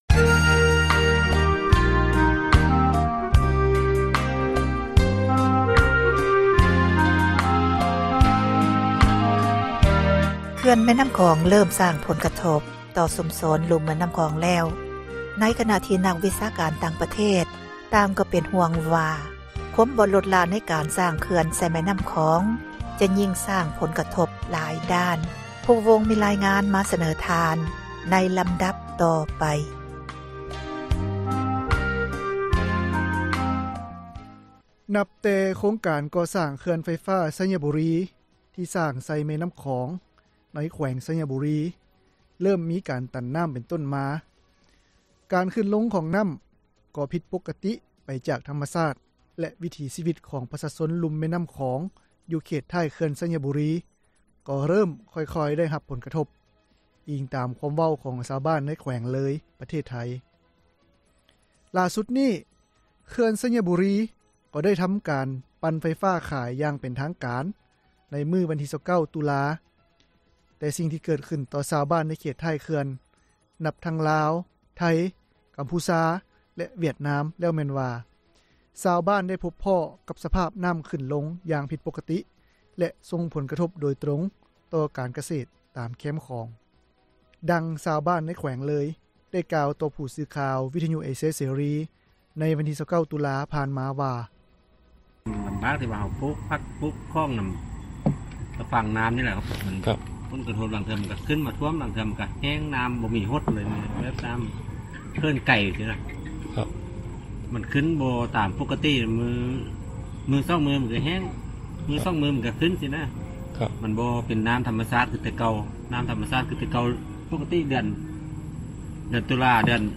ຊາວບ້ານທີ່ຍຶດອາຊີພ ຫາປາຕາມແຄມຂອງ, ເມືອງປາກຊົມ ແຂວງເລີຍ, ປະເທດໄທ ນາງນຶ່ງ ກໍໄດ້ໃຫ້ສັມພາດ ແກ່ຜູ້ສື່ຂ່າວວິທຍຸເອເຊັຽເສຣີ ໃນມື້ດຽວກັນວ່າ ຜົລກະທົບທີ່ເກີດຂຶ້ນ ກັບພວກເຂົາເຈົ້າ ໃນປະຈຸບັນນີ້, ກໍແມ່ນການ ຫາປາໄດ້ໜ້ອຍລົງ ແລະ ເກີດດິນເຈື່ອນເລື້ອຍໆ.